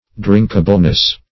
Drinkableness \Drink"a*ble*ness\, n.
drinkableness.mp3